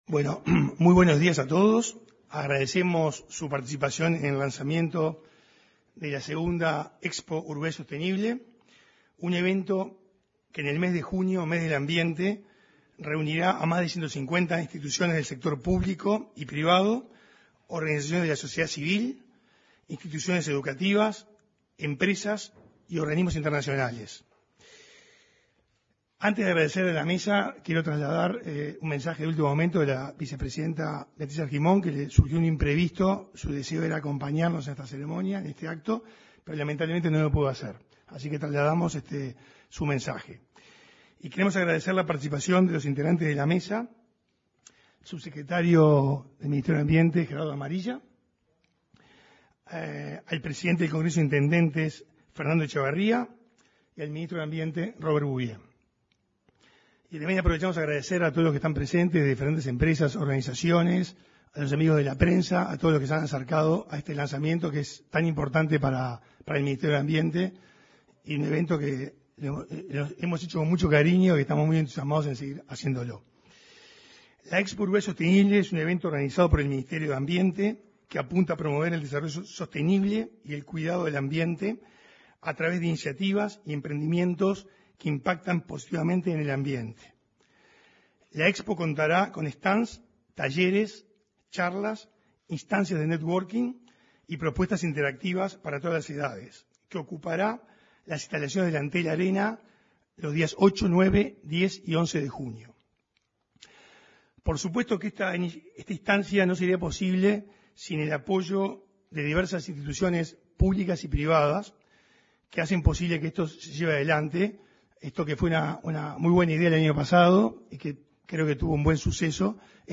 Este martes 18, en el salon de actos de la Torre Ejecutiva, el ministro de Ambiente, Robert Bouvier; el intendente de Flores y presidente del Congreso